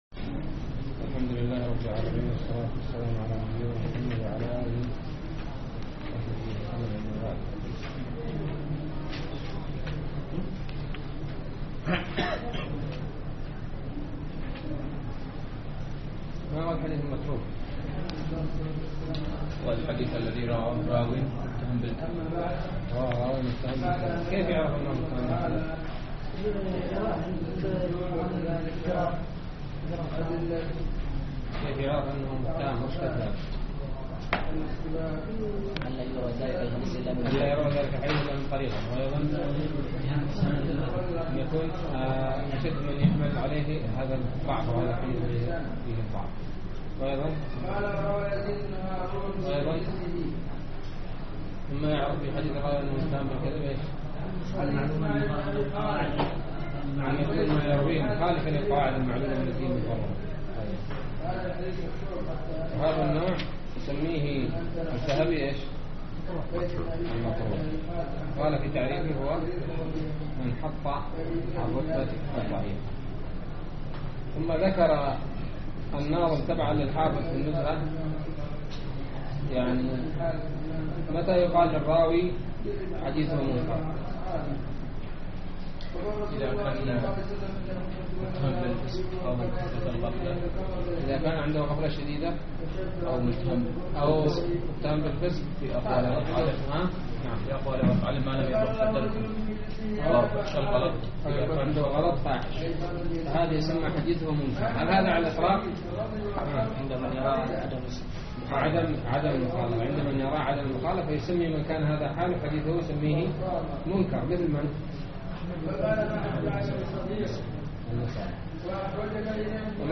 الدرس الحادي والعشرون من قصب السكر